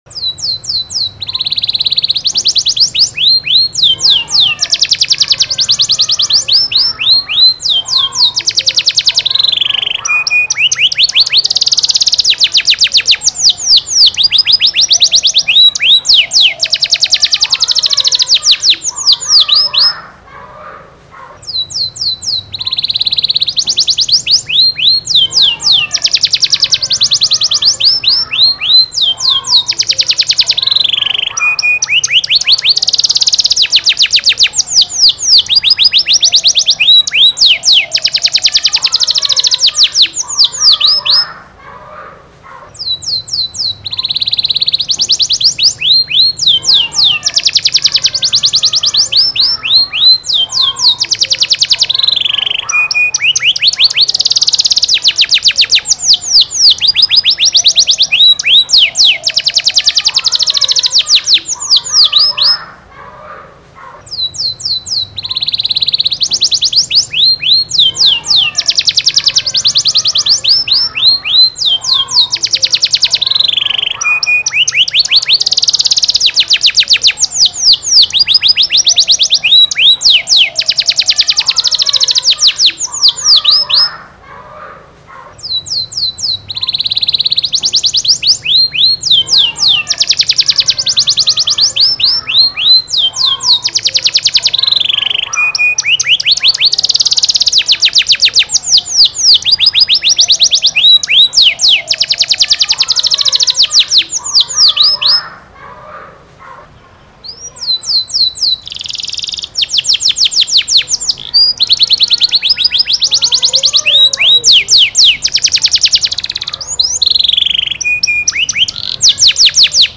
PANCINGAN KENARI GACOR AMPUH UNTUK KENARI MACET
DURASI PANJANG CENGKOK MEWAH TAJAM JERNIH
suara kenari